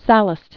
(săləst) Originally Gaius Sallustius Crispus. 86?-34?